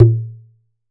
West MetroPerc (47).wav